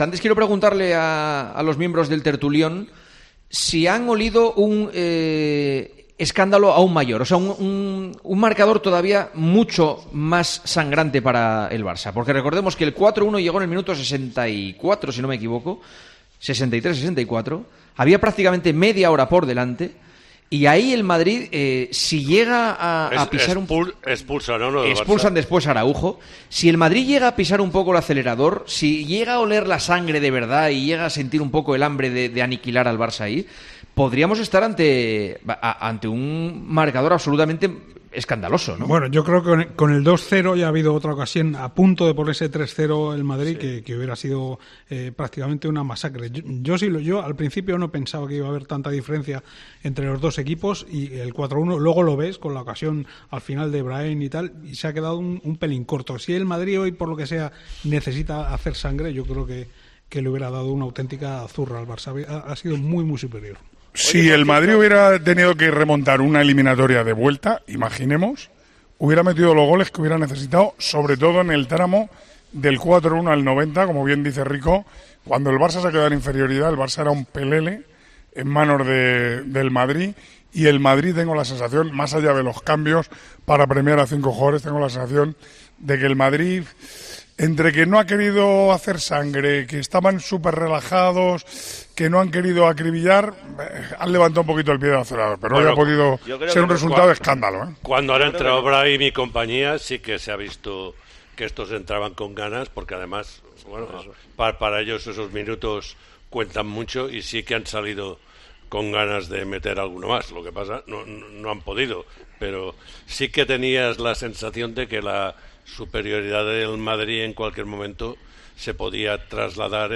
Juanma Castaño empezó 'El Tertulión de los domingos' preguntado a los tertulianos qué hubiera pasado si el Real Madrid hubiera apretado el acelerador en la segunda parte contra el Barcelona.